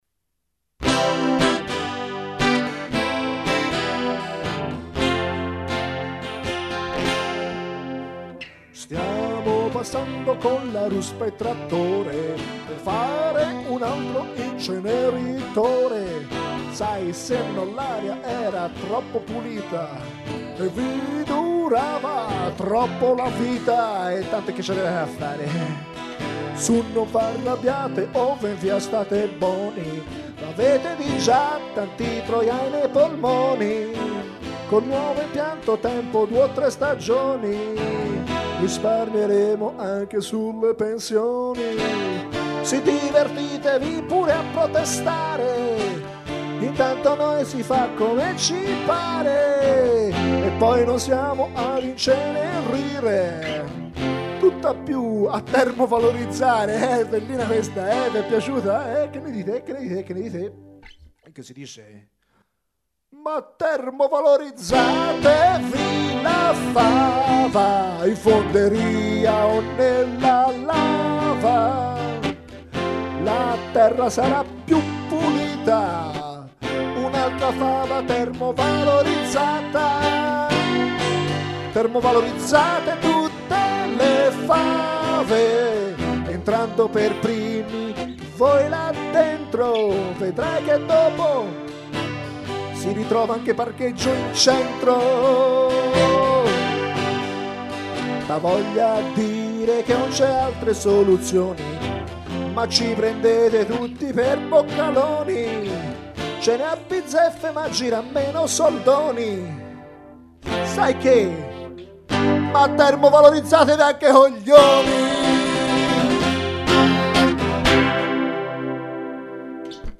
Cantata con passione, ascoltatela!